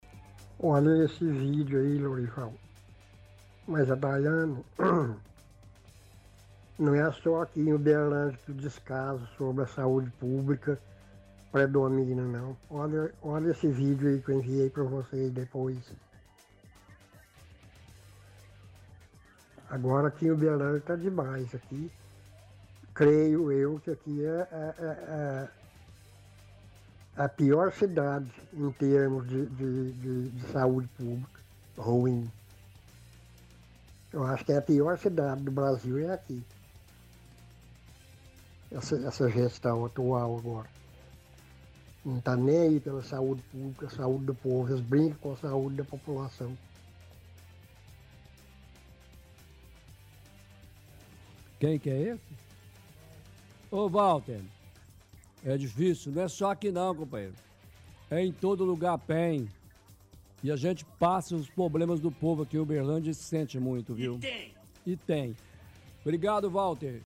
Descaso saúde pública diz que em Uberlândia é a pior cidade do Brasil em saúde nesta gestão repórter faz comentário.